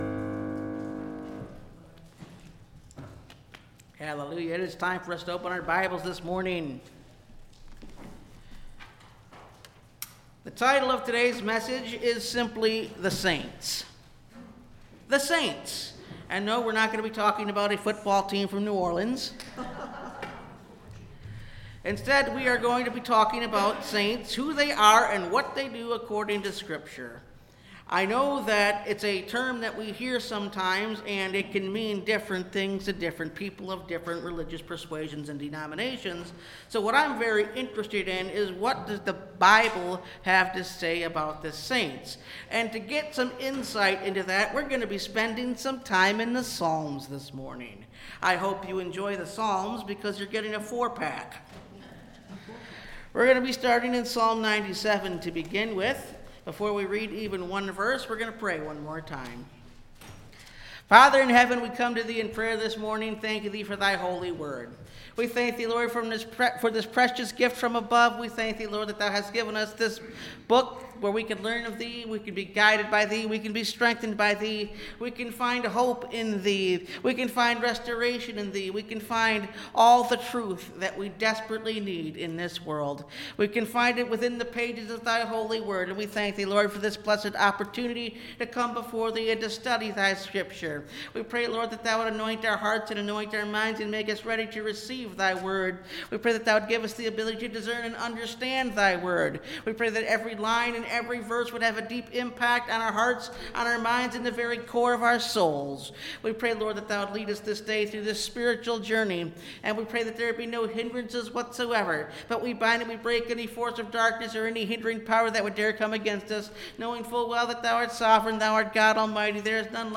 The Saints (Message Audio) – Last Trumpet Ministries – Truth Tabernacle – Sermon Library